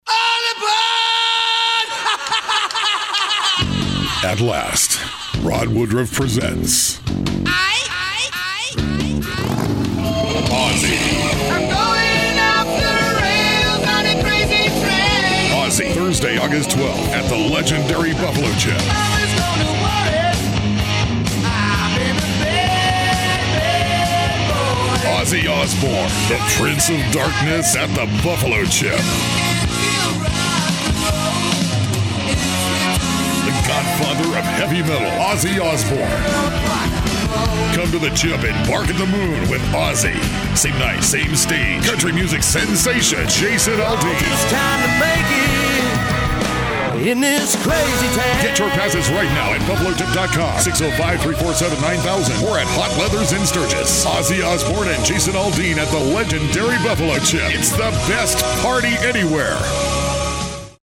Some of the many Radio spots we have created: